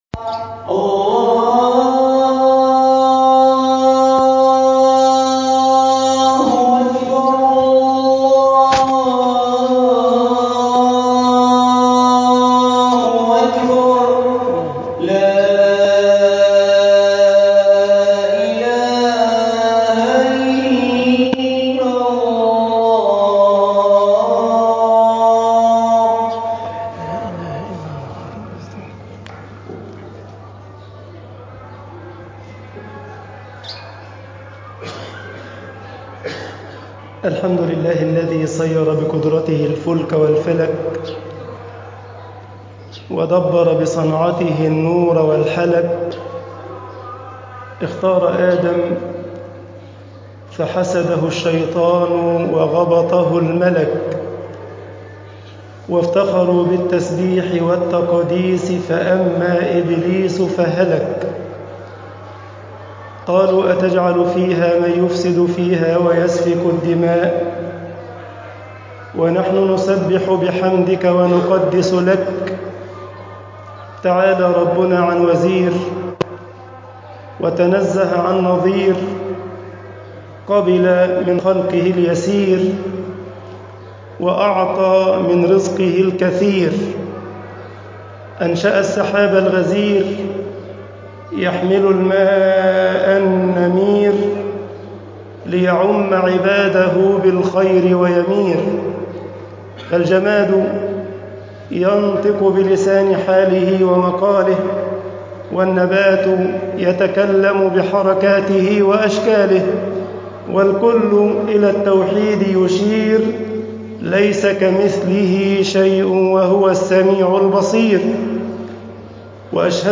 خطب الجمعة - مصر